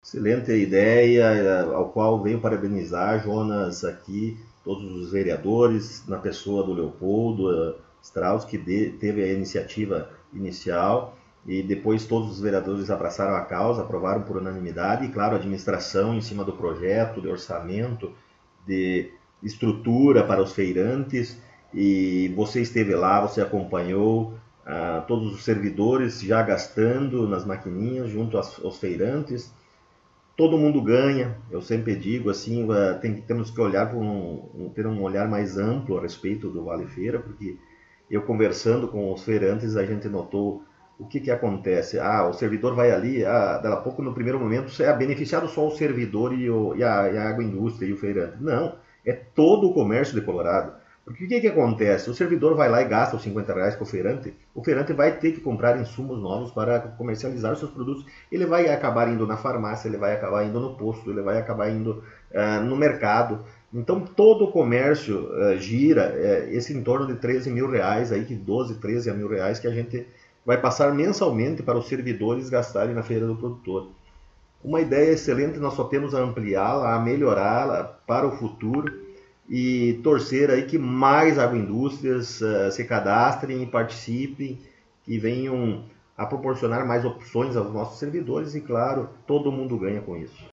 O Colorado em Foco esteve no Gabinete da Prefeitura Municipal para entrevistar o Prefeito Rodrigo e saber das últimas informações de trabalhos, atividades e programas do Governo Municipal.